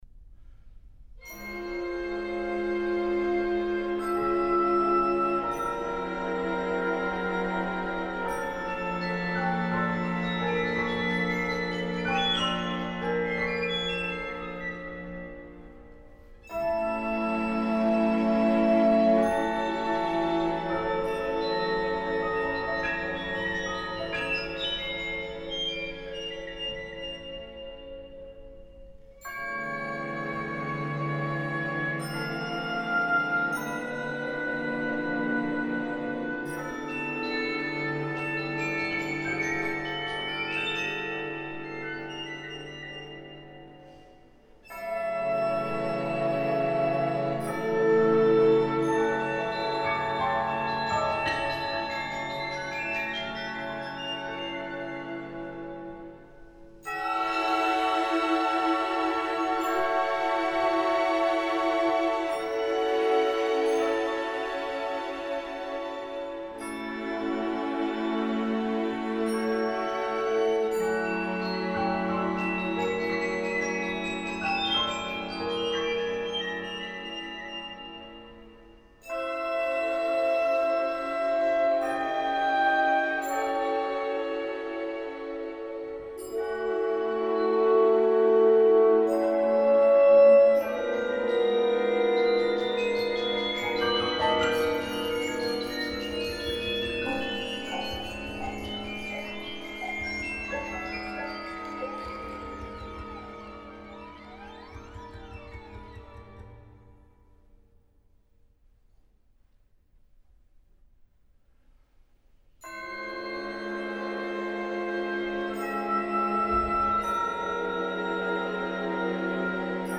Klangbeispiele (Orchester)
von Hochschulorchester der HfM Detmold | 8. Satz - "Les ressucités"